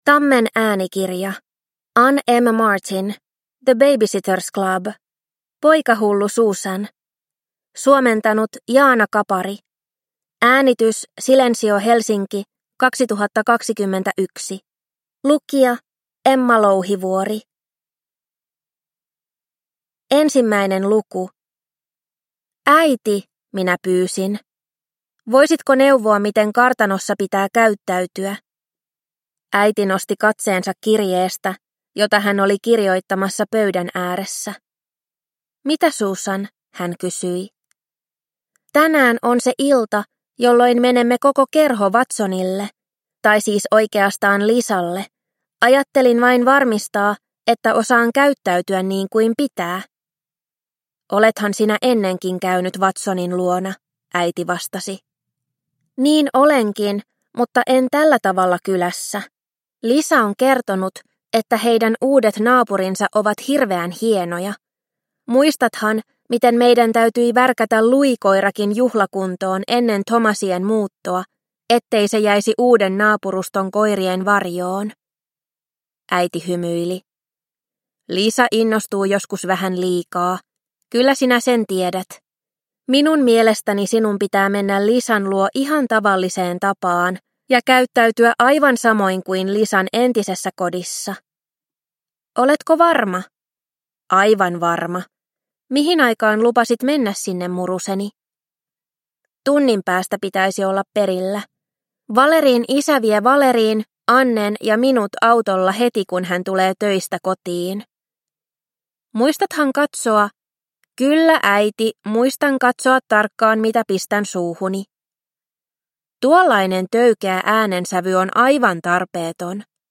The Baby-Sitters Club. Poikahullu Susan – Ljudbok – Laddas ner